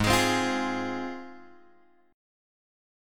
G# 6th